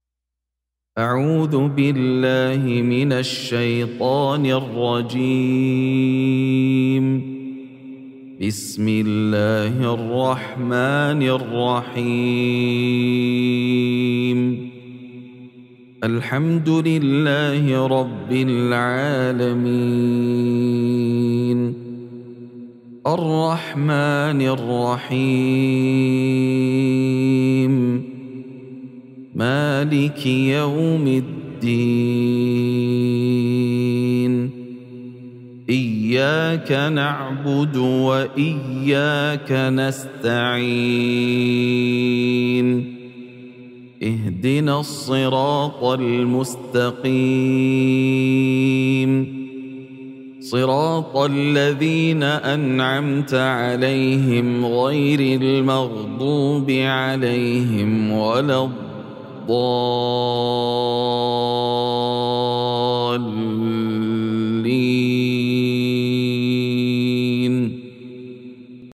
سورة الفاتحة | مصحف المقارئ القرآنية > الختمة المرتلة ( مصحف المقارئ القرآنية) > المصحف - تلاوات ياسر الدوسري